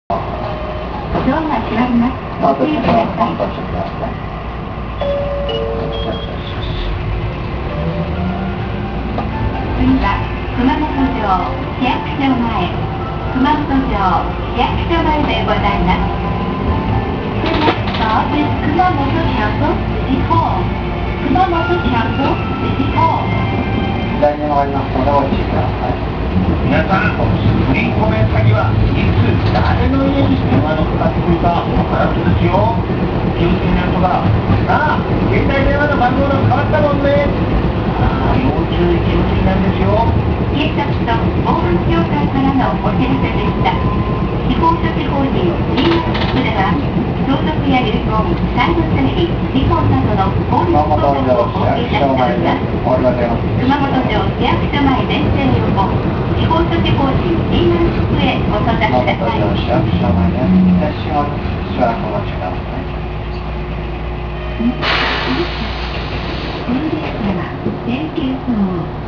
〜車両の音〜
・8500形走行音
【Ａ系統】通町筋→熊本城・市役所前（1分9秒：376KB）
見た目に反した吊り掛け式。